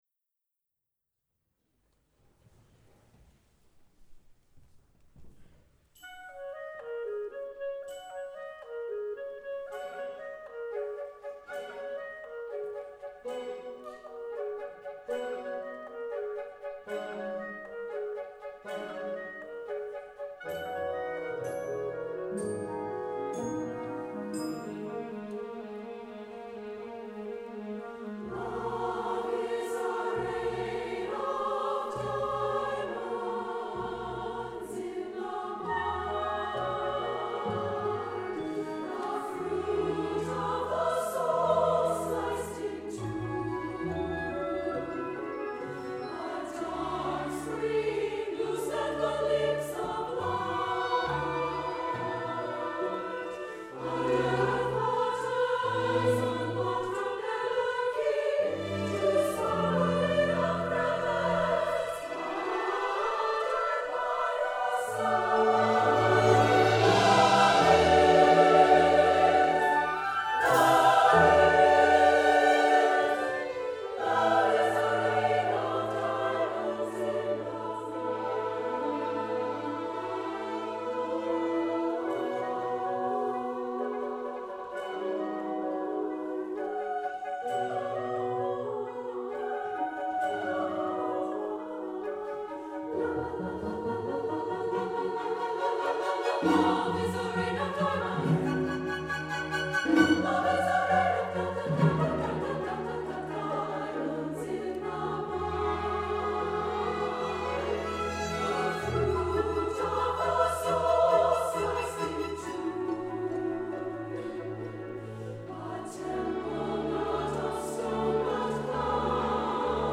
for SSAA Chorus and Chamber Orchestra (2004)